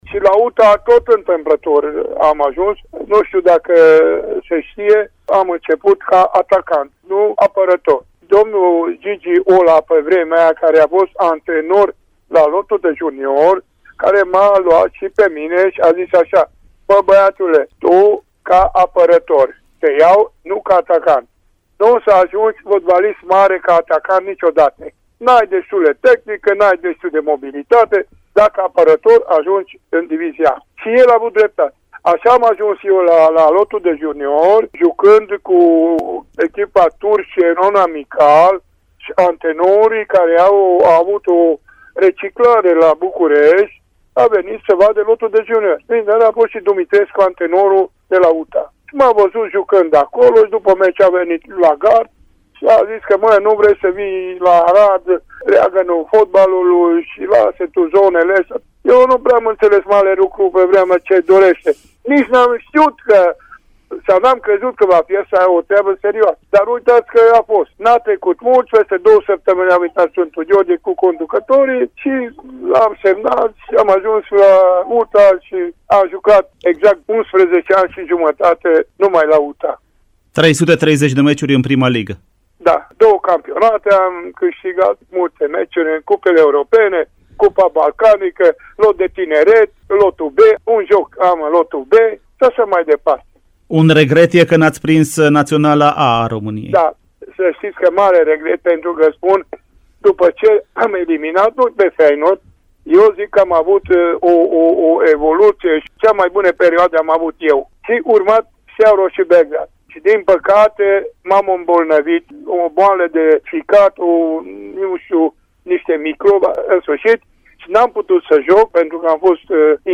Pe lângă declarațiile perioadei actuale, dinaintea jocului cu Sepsi, de mâine seară ora 20, vă propunem ”voci” ale Bătrânei Doamne, din fonoteca Radio Timișoara.